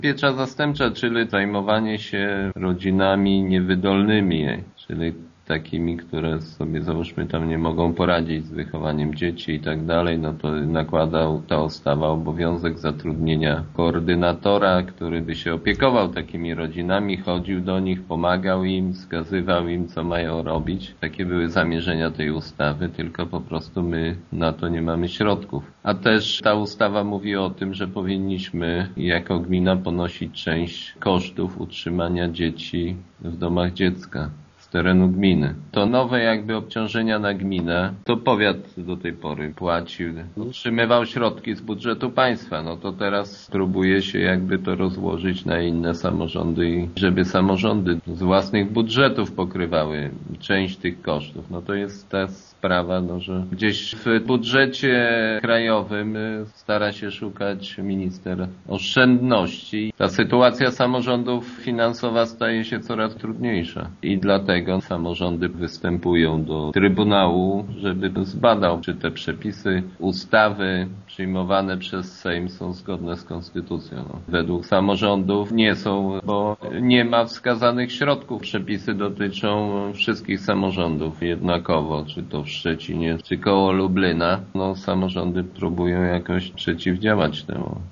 – To problem formalnie podniesiony przez radnych ze Starogardu, ale sprawa dotyczy wszystkich samorządów – mówi wójt Gminy Głusk Jacek Anasiewicz: